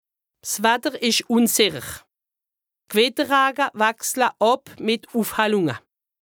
Haut Rhin
Ville Prononciation 68
Bruebach